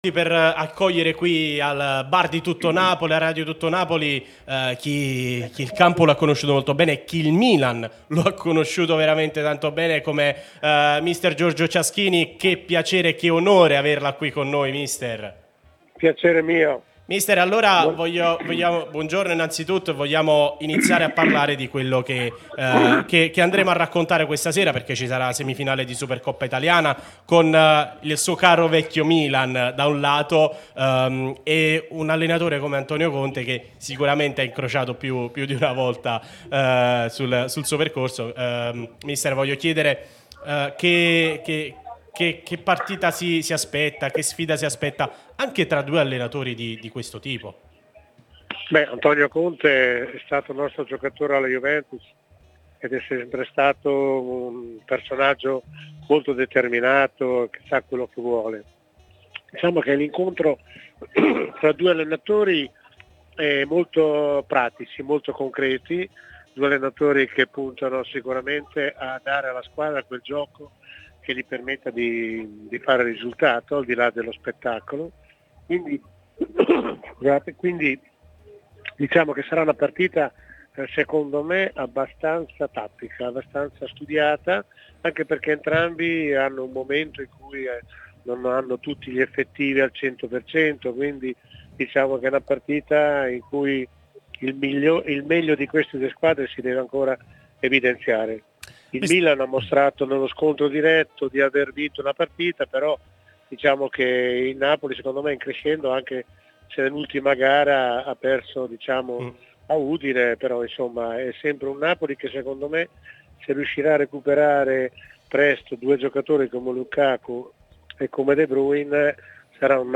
trasmissione sulla nostra Radio Tutto.